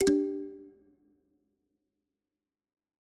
power-unplug.ogg